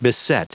Prononciation du mot beset en anglais (fichier audio)
Prononciation du mot : beset